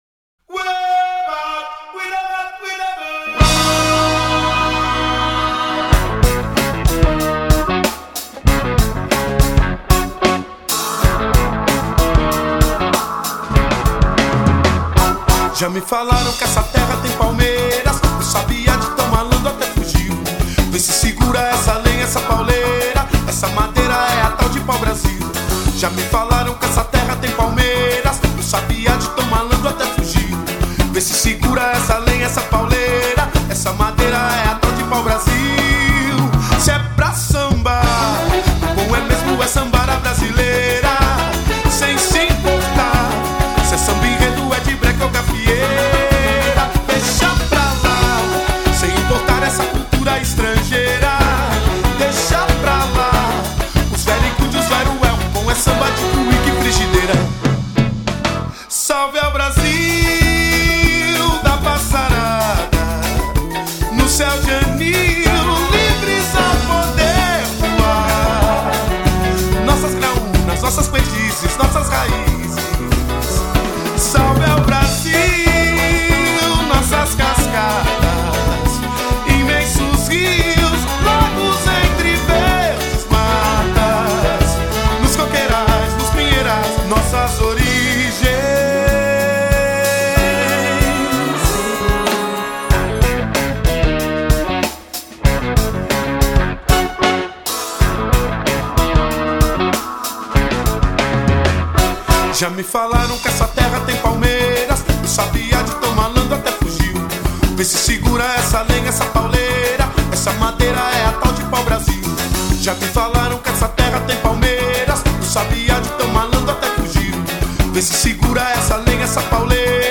EstiloSamba Rock